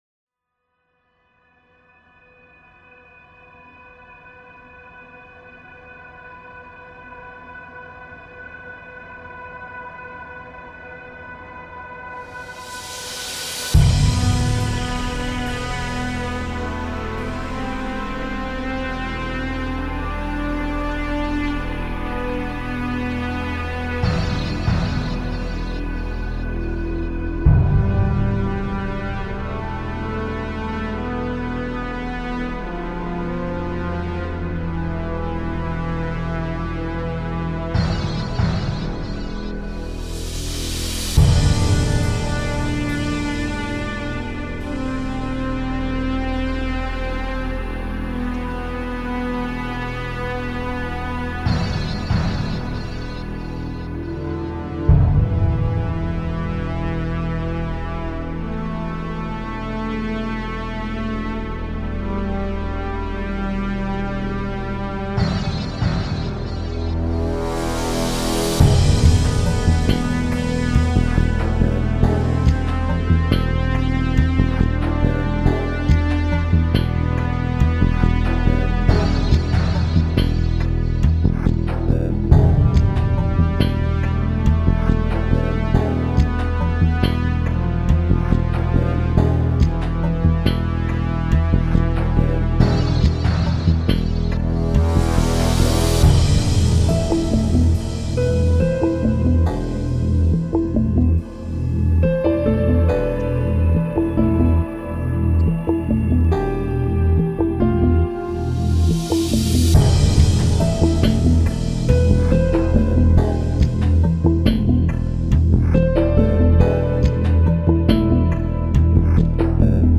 来自另一个世界的下一代科幻声音和音轨。
此免版税的声音包总共包含15条音轨，这些音轨也分为各自的部分（鼓，旋律，fx，打击，合唱等等）。